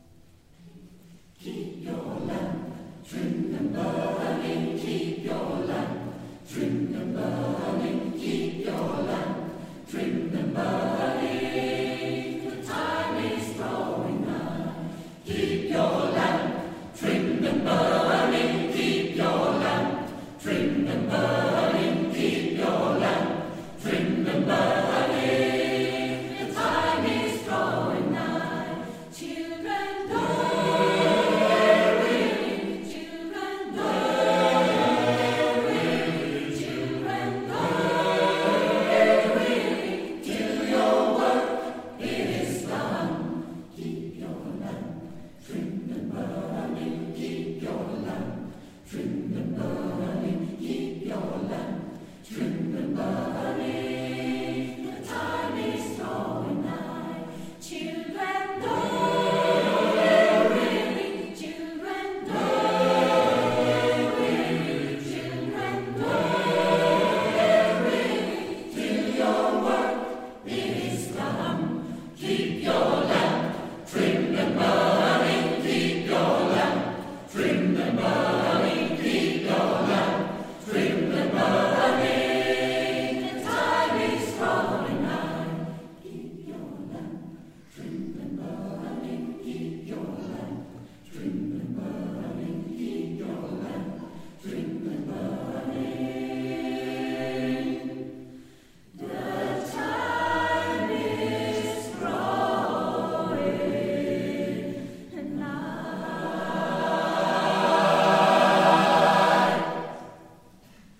Nyårskonsert Ängelholms kyrka 2024